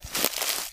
High Quality Footsteps
STEPS Bush, Walk 01.wav